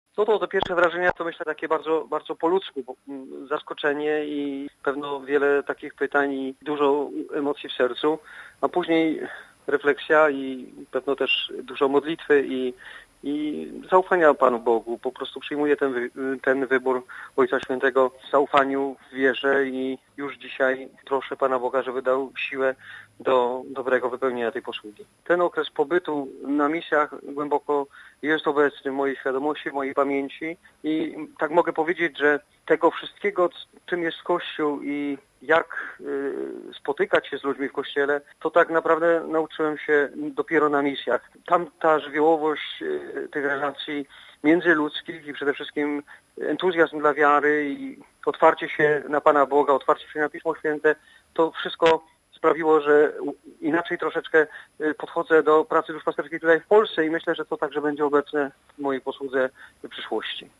W rozmowie z Radiem Watykańskim biskup nominat Leszek Leszkiewicz podzielił się pierwszymi wrażeniami po otrzymaniu papieskiej nominacji i swoim doświadczeniem z pracy misyjnej: